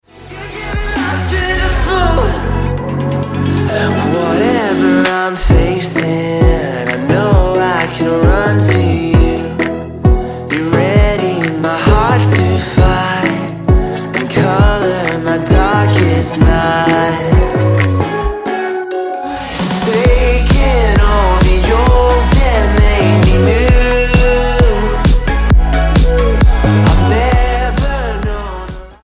Style: Dance/Electronic Approach: Praise & Worship